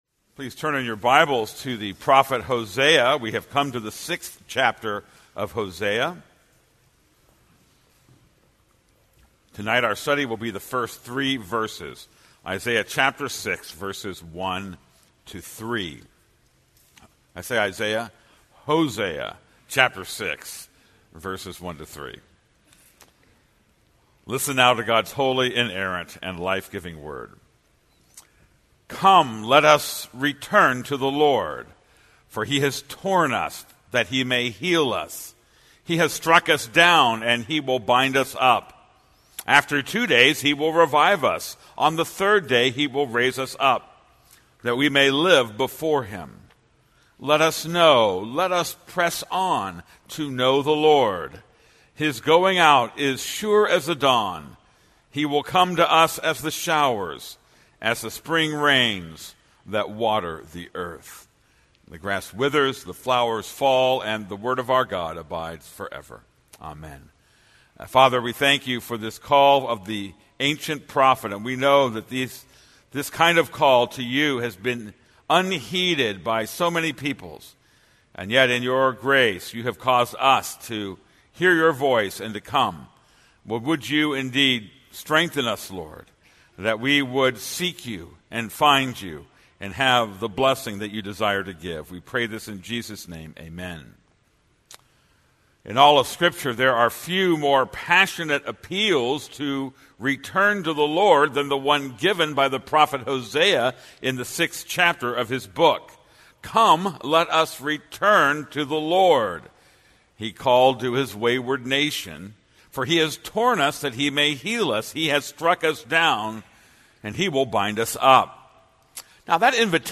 This is a sermon on Hosea 6:1-3.